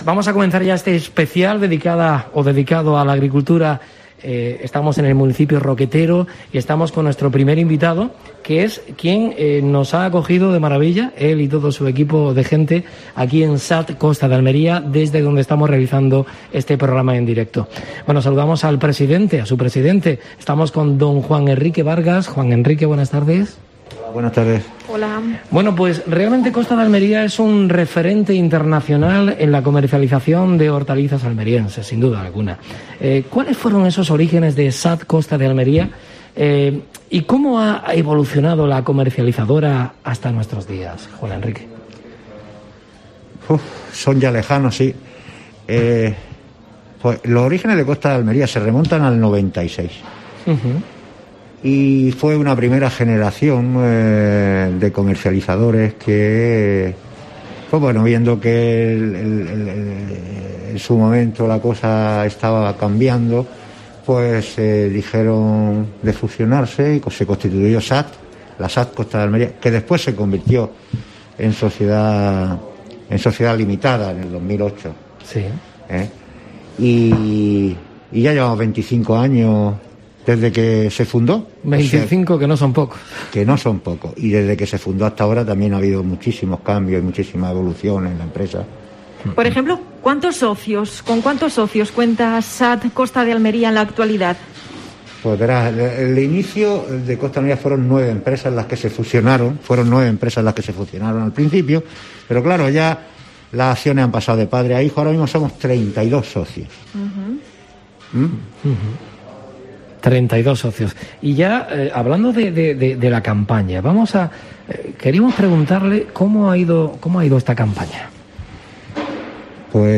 La I Semana de la agricultura en Roquetas de Mar, que organiza COPE Almería, ha comenzado este lunes con el primer programa realizado desde la SAT Costa de Almería.